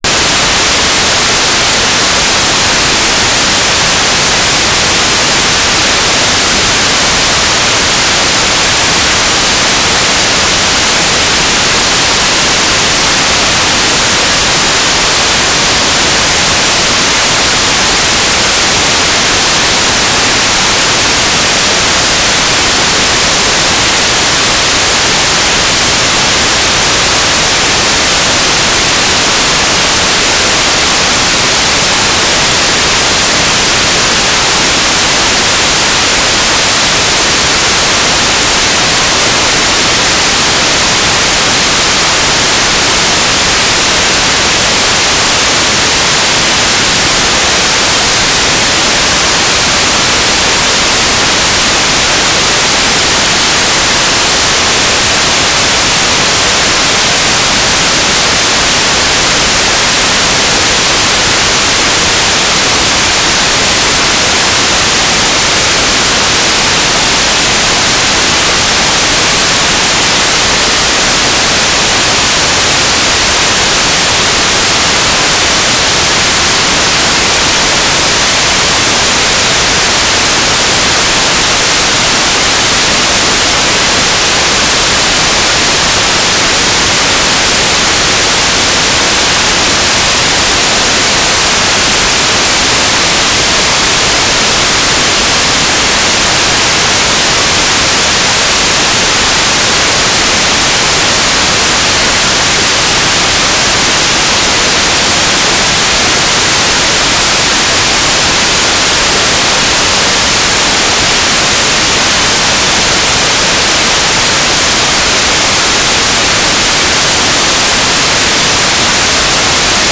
"transmitter_description": "Main telemetry transmitter",
"transmitter_mode": "GMSK USP",
"transmitter_baud": 2400.0,